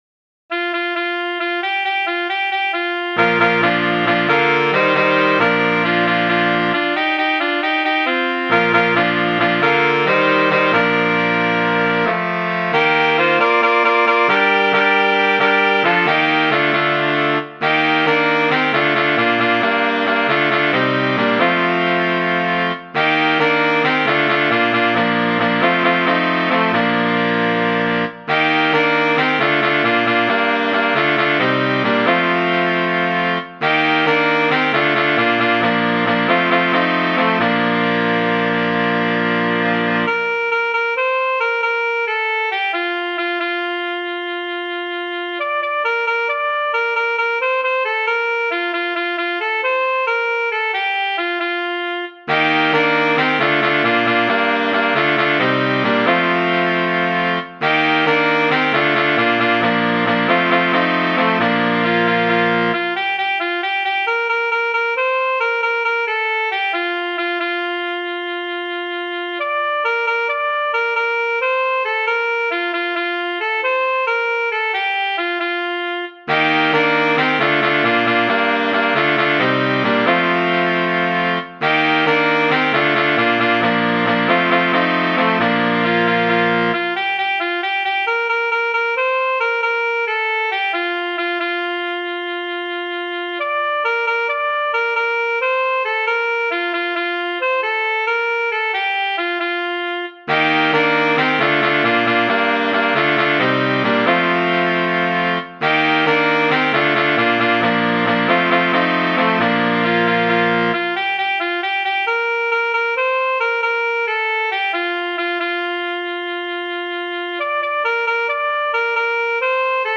Chants de Pâques Téléchargé par